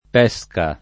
DOP: Dizionario di Ortografia e Pronunzia della lingua italiana
p$Ska] s. f. («frutto») — es. con acc. scr.: a le tue guance di pèsca [a lle tue gU#n©e di p$Ska] (Aleardi); Le pèsche e i fichi su la chiara stoia [le p$Ske e i f&ki Su lla kL#ra St0La] (D’Annunzio); uno spicchio di pèsca [uno Sp&kkLo di p$Ska] (Cicognani) — cfr. giro pesca